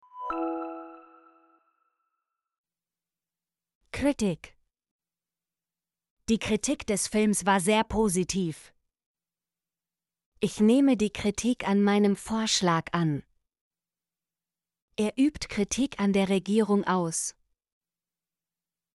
kritik - Example Sentences & Pronunciation, German Frequency List